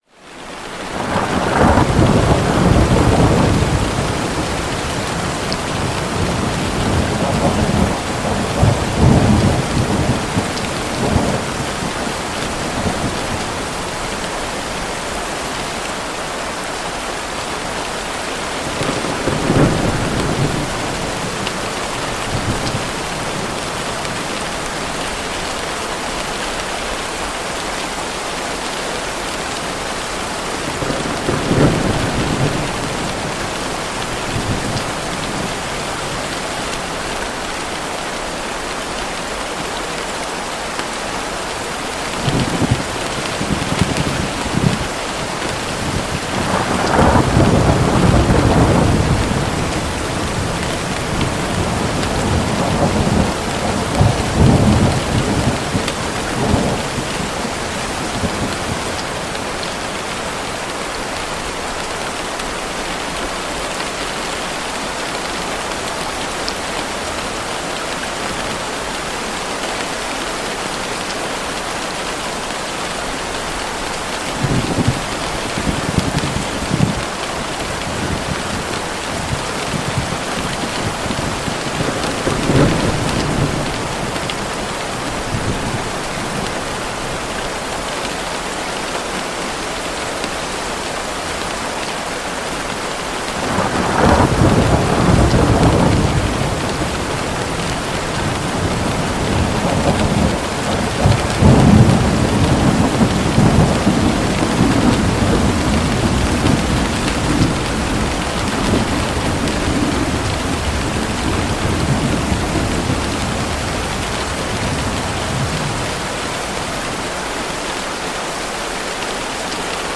Starker Regen & Donner zum Einschlafen – Sturm am Fenster mit ASMR
Ein einzelner Regentropfen durchbricht die absolute Stille und beginnt seine epische Reise vom dunklen Gewitterhimmel hinab auf das warme Dach Ihres sicheren Zeltes im tiefen Wald.